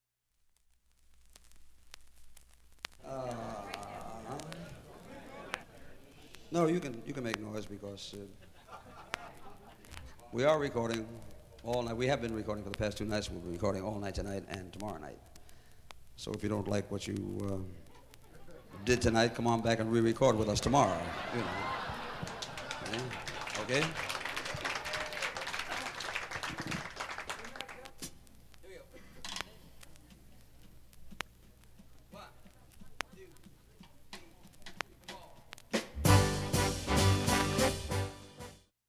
単発のかすかなプツが4箇所
ハリウッドのシェズ・クラブ におけるライヴ録音